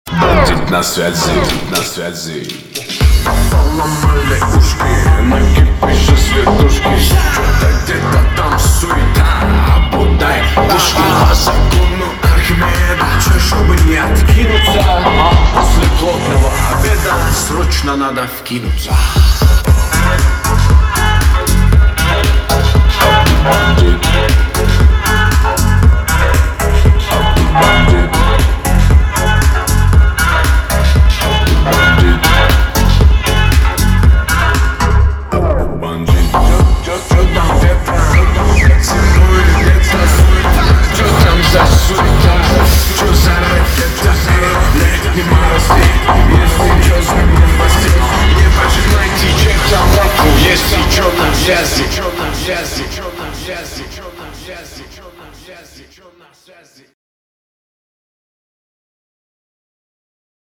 • Качество: 320, Stereo
веселые
Заводная весёлая песня на рингтон